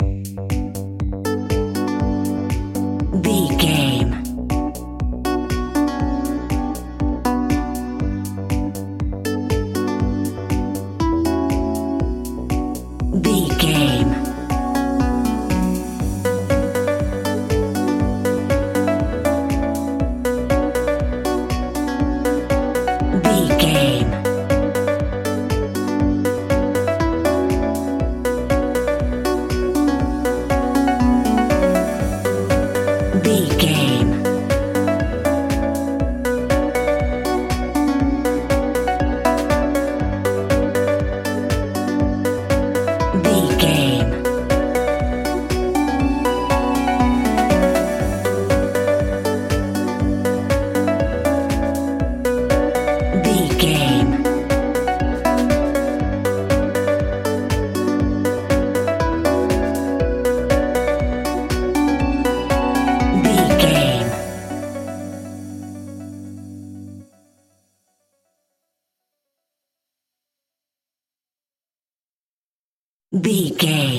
Aeolian/Minor
Slow
groovy
peaceful
tranquil
meditative
smooth
drum machine
electric guitar
synthesiser
synth pop
synth leads
synth bass